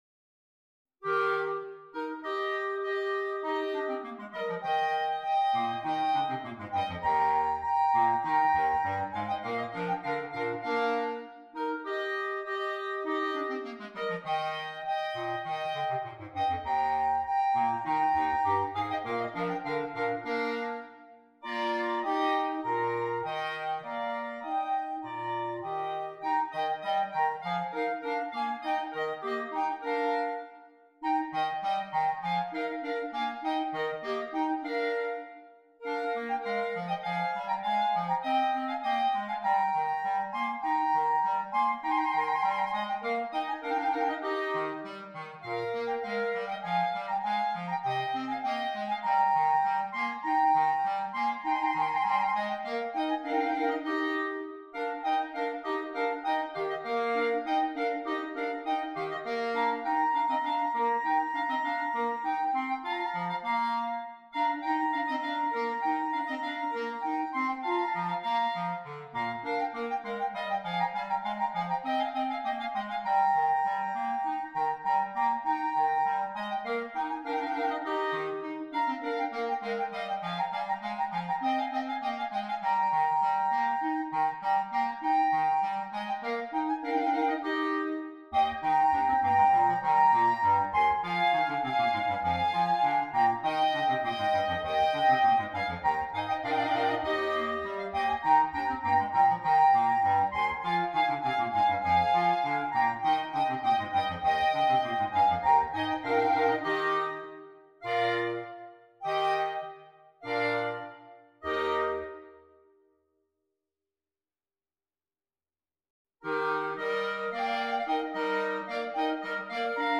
6 Clarinets